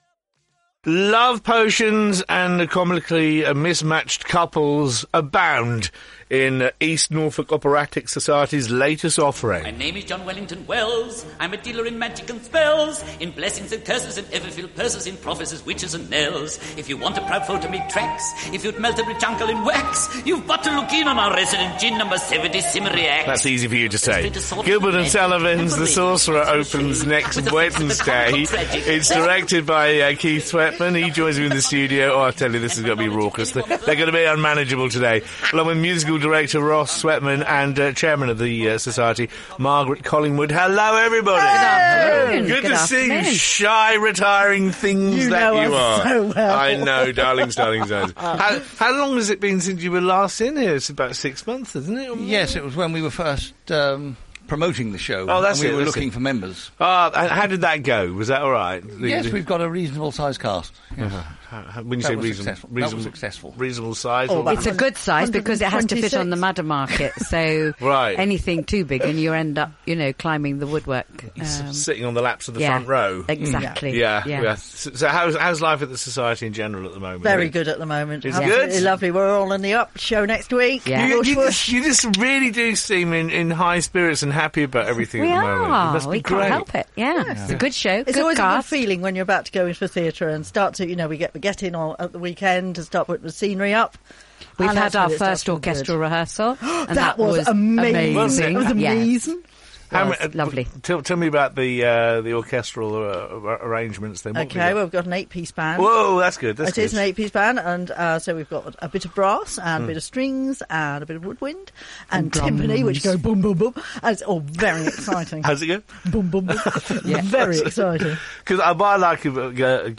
Documents Click on image Programme Cover Click on image Publicity Poster The Sorcerer Synopsis NODA Review Unofficial NODA Review Listen to the Publicity Interview broadcast on BBC Radio Norfolk on 30 April 2015